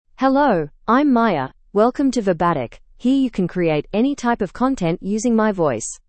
FemaleEnglish (Australia)
Maya is a female AI voice for English (Australia).
Voice sample
Listen to Maya's female English voice.
Maya delivers clear pronunciation with authentic Australia English intonation, making your content sound professionally produced.